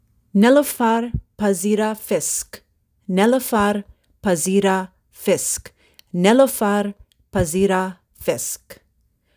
(Avec prononciation audio)